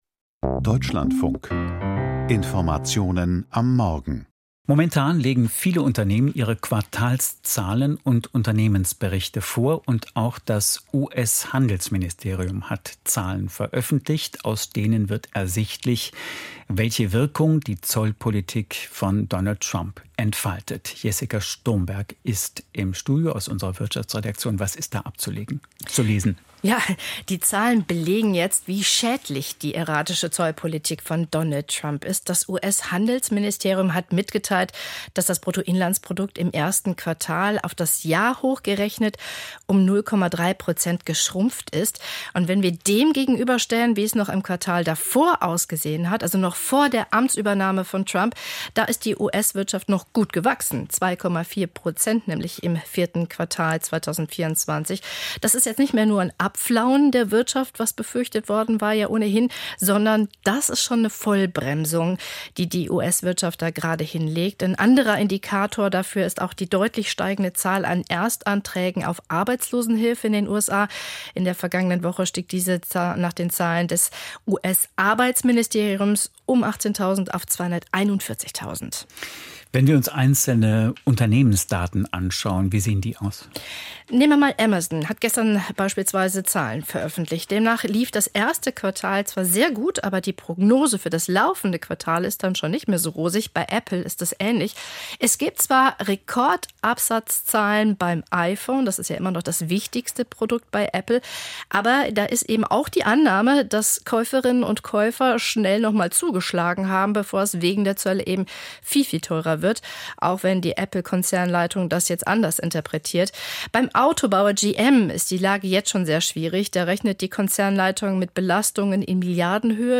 Wirtschaftsgespräch: BASF-Hauptversammlung und Veröffentlichung Q1-Zahlen